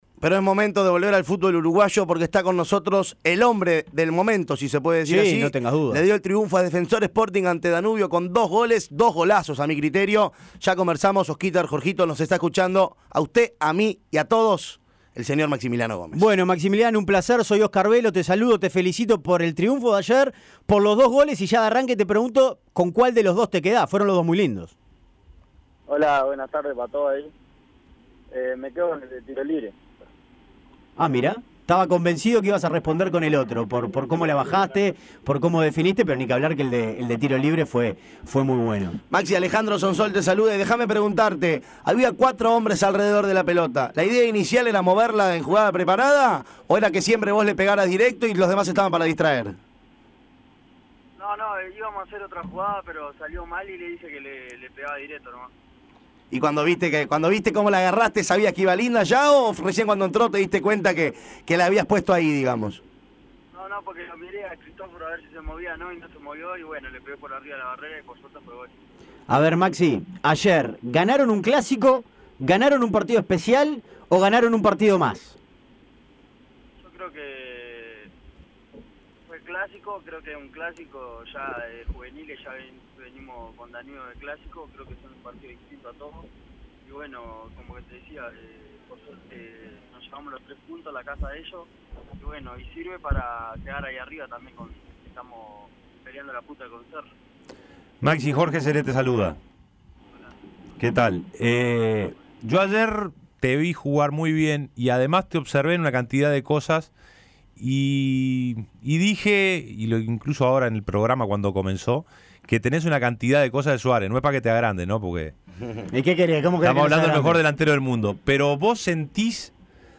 El joven delantero de Defensor Sporting, Maximiliano Gómez (20 años), atraviesa un gran momento en lo individual, está con la pólvora seca y Defensor es puntero. El goleador habló en Tuya y Mía de todo.